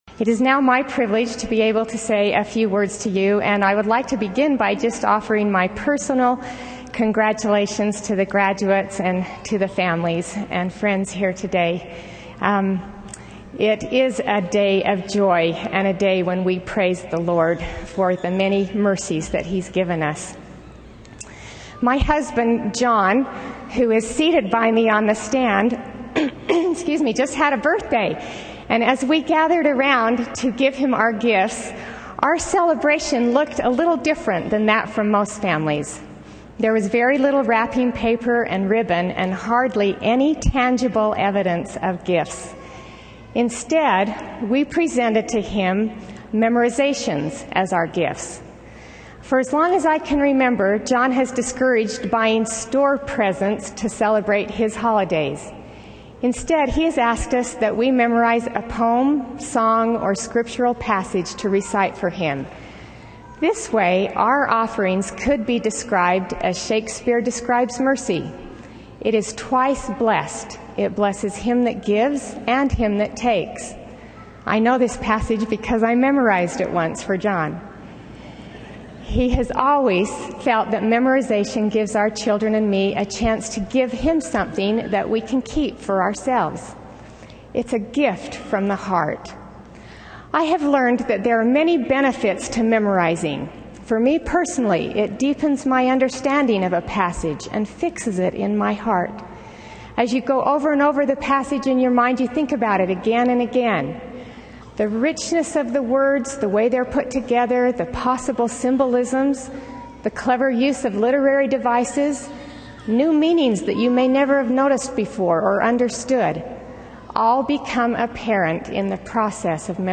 Commencement